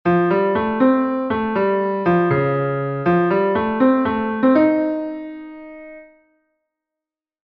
Allo modo ma con fuoco.
Musique